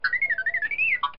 Звуки дроида R2D2 из звёздных войн в mp3 формате